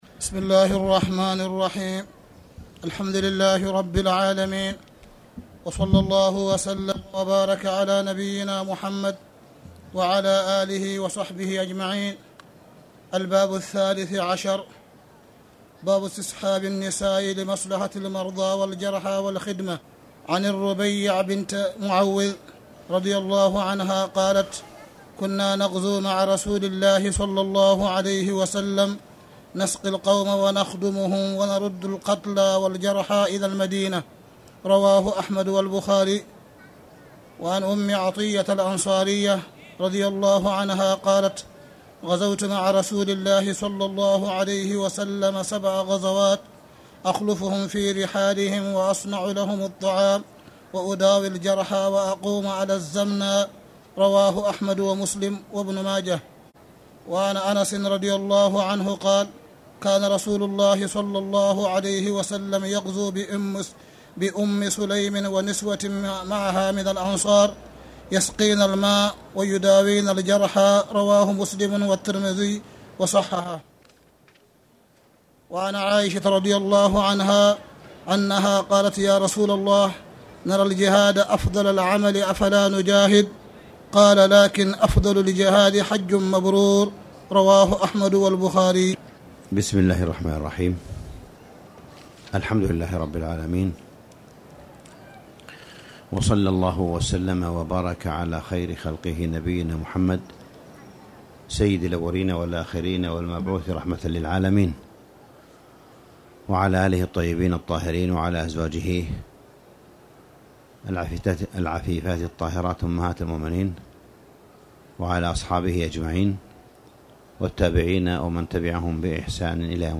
تاريخ النشر ٣ رمضان ١٤٣٧ هـ المكان: المسجد الحرام الشيخ: معالي الشيخ أ.د. صالح بن عبدالله بن حميد معالي الشيخ أ.د. صالح بن عبدالله بن حميد نيل الاوطار كتاب الجهاد (2)